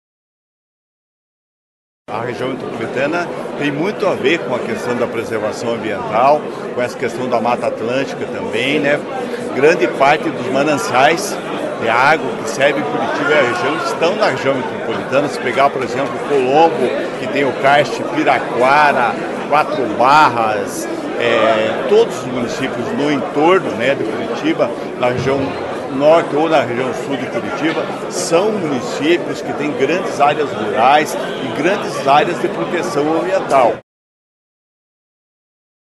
Além de representantes dos governos estaduais, diversos municípios também estiveram presentes na abertura da conferência, caso do prefeito de Colombo e presidente da associação dos municípios da região metropolitana de Curitiba, Helder Lazarotto.